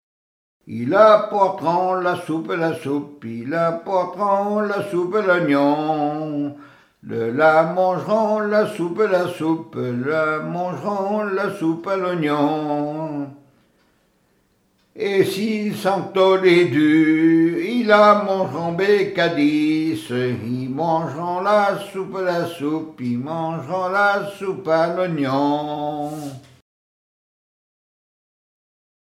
témoignages sur le mariage et chansons de circonstance
Pièce musicale inédite